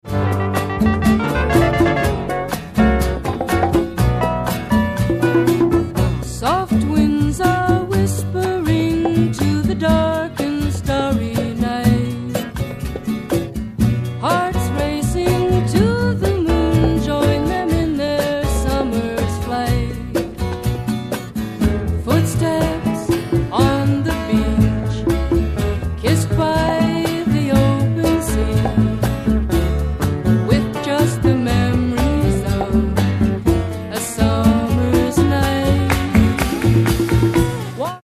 ACID FOLK/RARE GROOVE